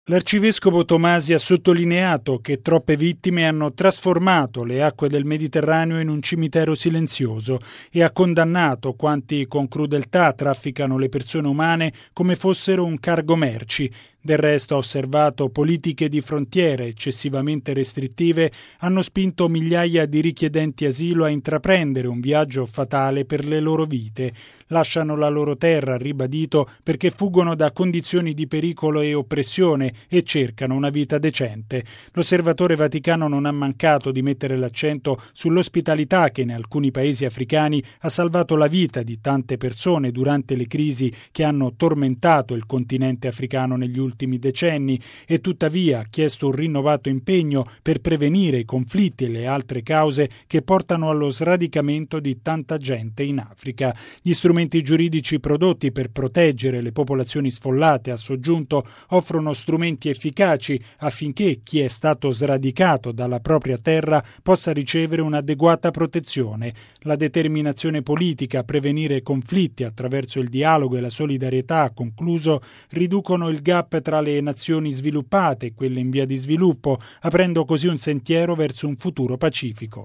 E’ quanto sottolineato dall’arcivescovo Silvano Maria Tomasi, osservatore permanente della Santa Sede presso l’Onu di Ginevra, intervenuto oggi alla 65.ma sessione dell’Alto Commissariato per i Rifugiati delle Nazioni Unite (Acnur), con particolare riferimento alla situazione in Africa.